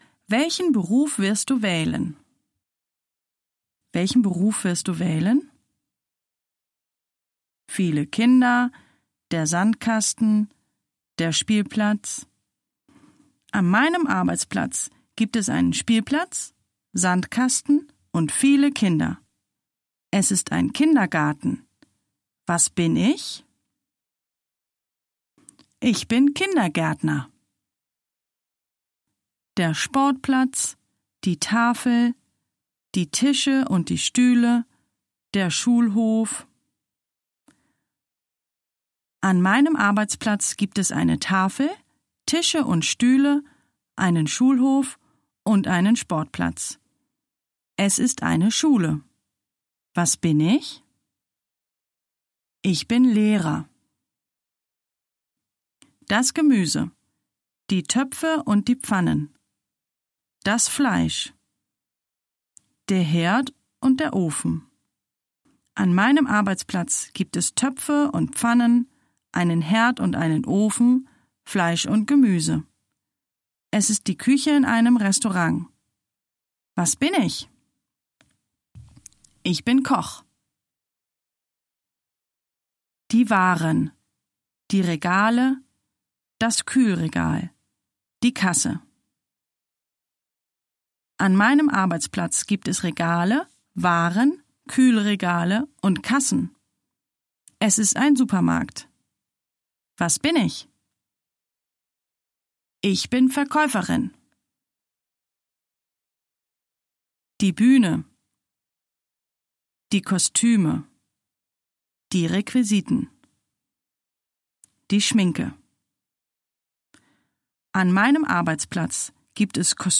Scan QR-koden på side 3 - eller klik her - for at høre højtlæsning